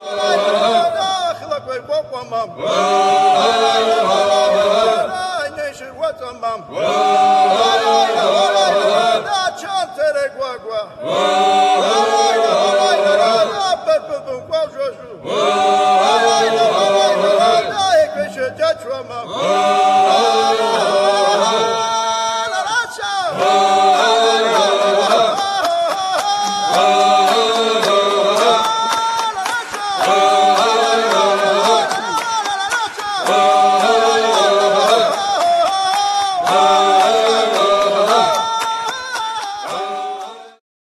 Nagranie koncertowe.